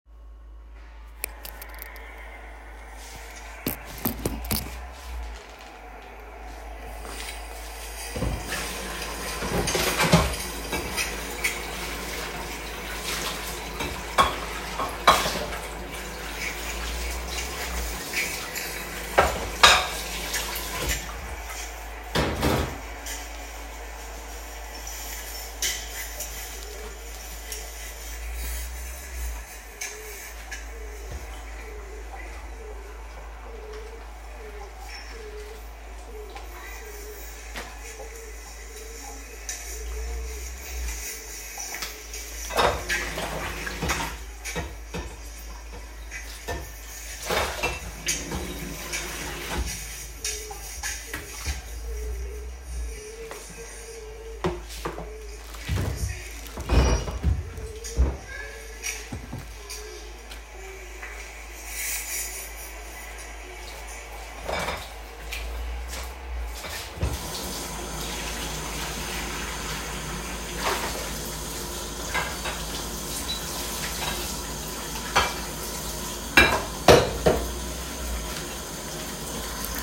As the third participant, I created my work on the basis of the material recorded by the first two students: the initial ethereal reverberation of Edinburgh Castle, the subtle sound of pigeon-like chirping in the fireplace inside the wall, and the contemporary noise of the cars whizzing by on the roadside have already constituted an intertwined “soundscape” between the past and the present. On top of this, I added the sound of “cooking” – a highly everyday act, but one that appears unusually abrupt in the unfamiliar soundscape. The clash of pots and pans and the bubbling of boiling oil seem to pull the viewer out of the space of a medieval castle or a city street, and re-anchor them in a warm, inhabited reality.